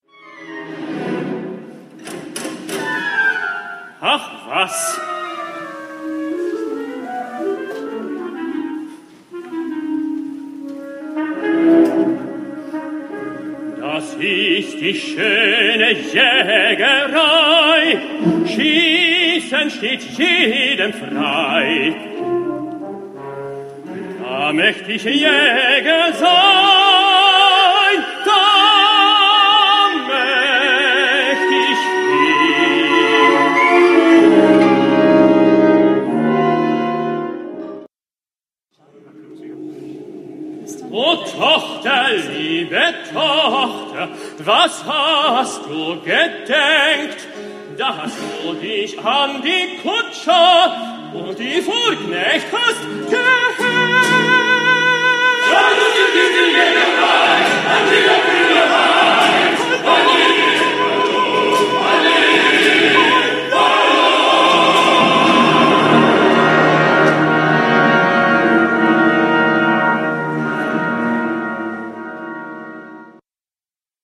Andres
GLASGOW
City Halls